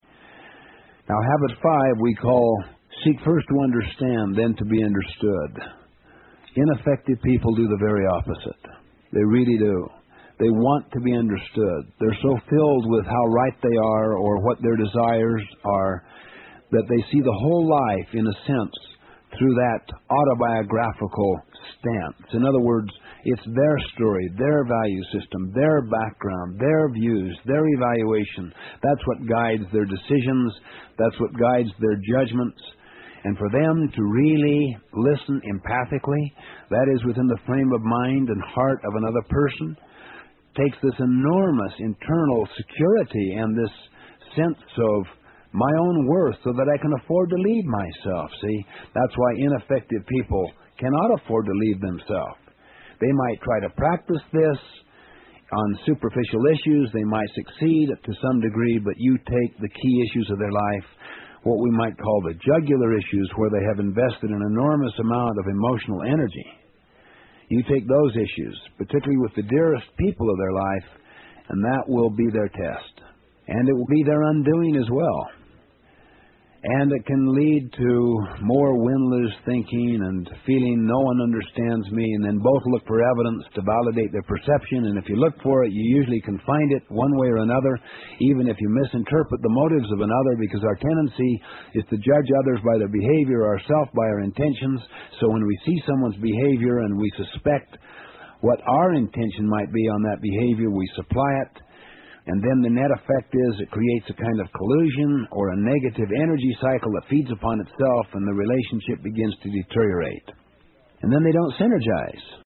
有声畅销书：与成功有约06 听力文件下载—在线英语听力室